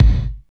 32.03 KICK.wav